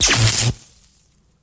blast_miss.wav